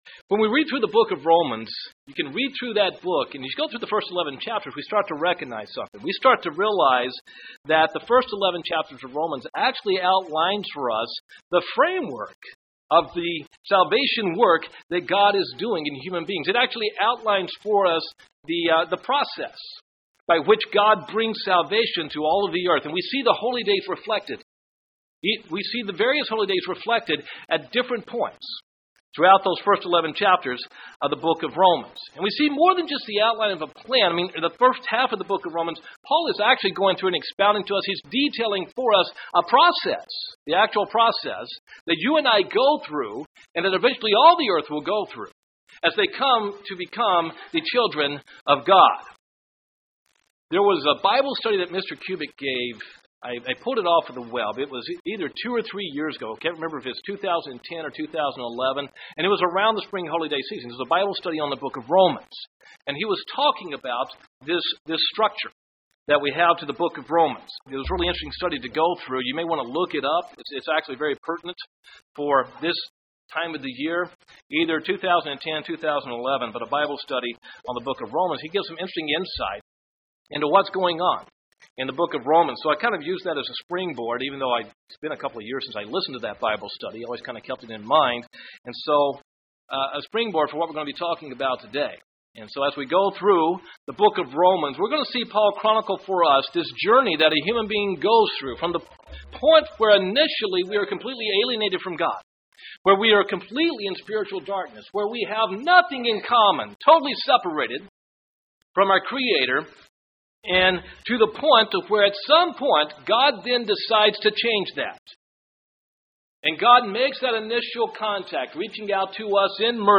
Given in Huntsville, AL
UCG Sermon Studying the bible?